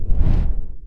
flap.wav